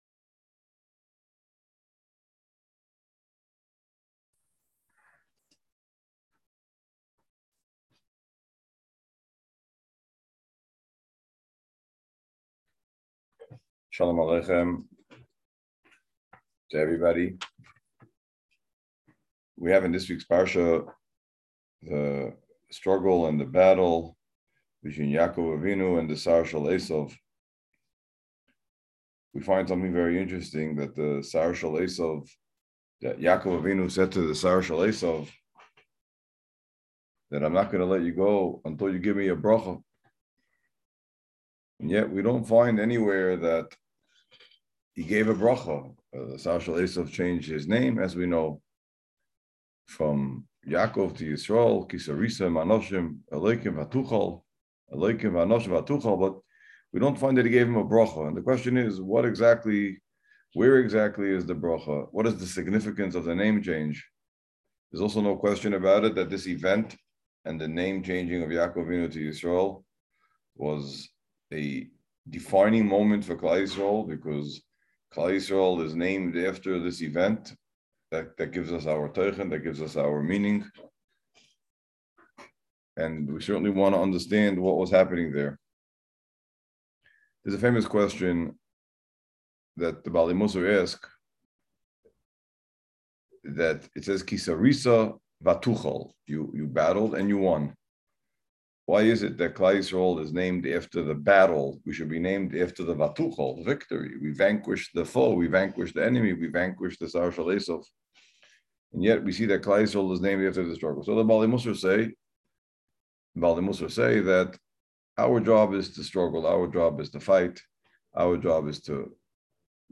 Parsha Preview Audio